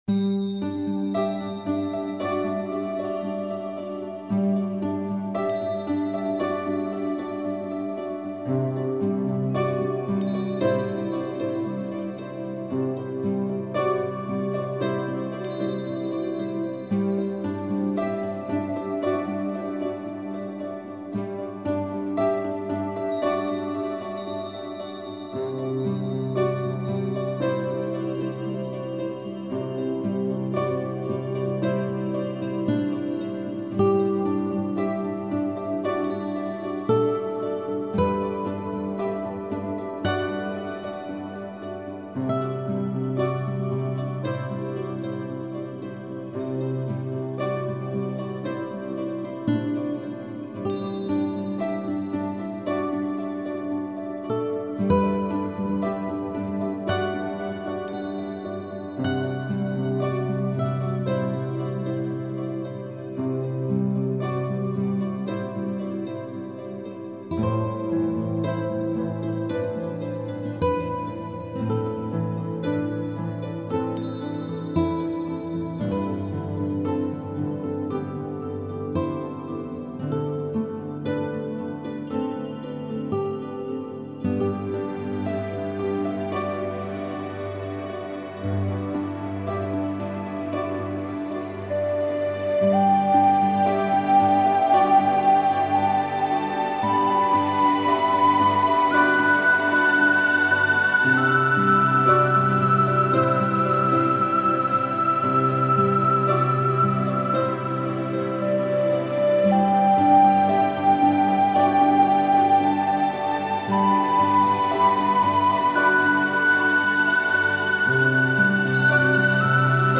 徹底解放的芬多精舒壓
獨立研發的三次元超立體錄音系統 「CYBER．PHONIC」